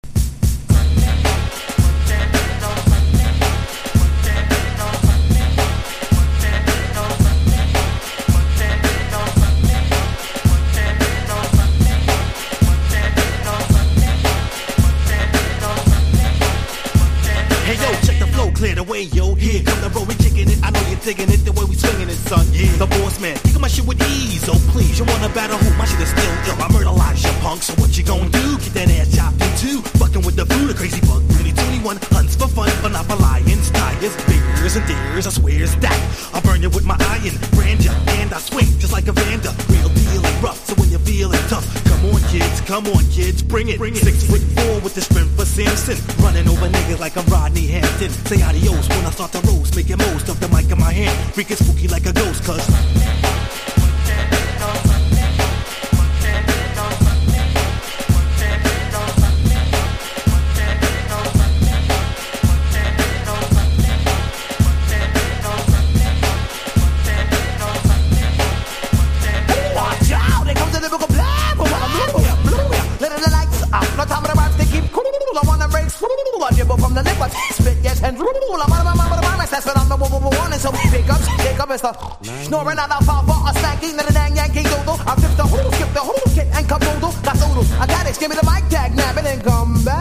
元祖早口RAP